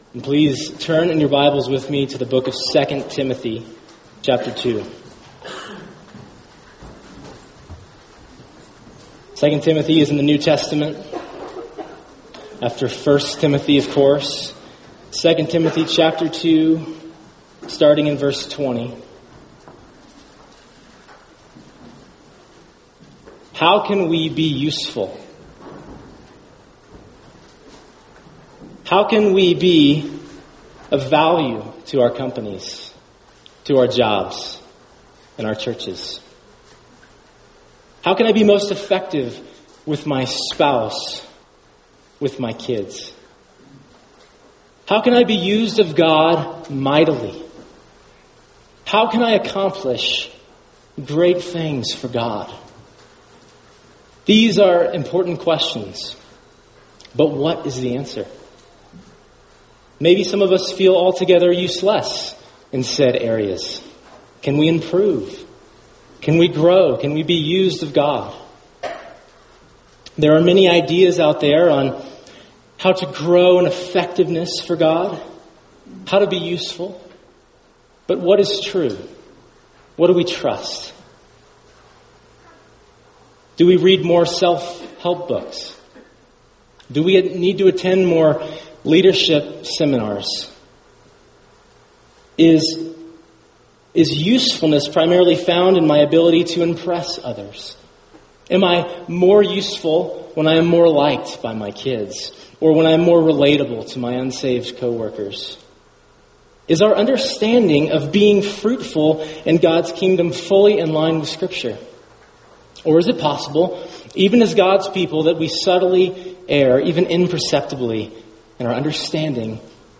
[sermon] 2 Timothy 2:20-26 Useful to the Master | Cornerstone Church - Jackson Hole